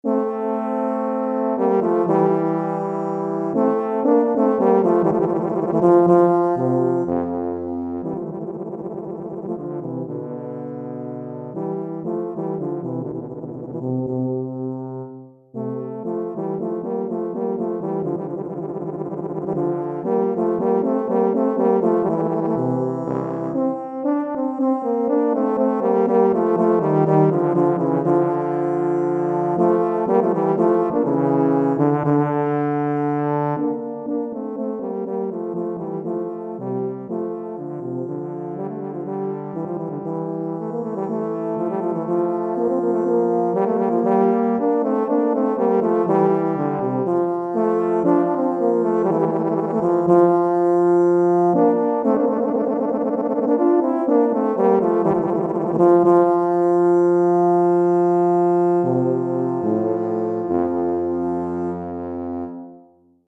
Recueil pour Tuba, euphonium ou saxhorn - 2 Tubas